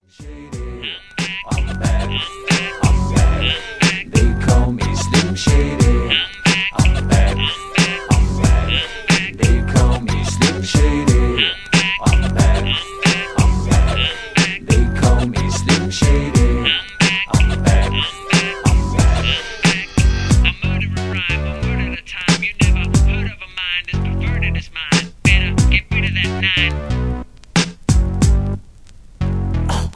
rap, hip hop, rock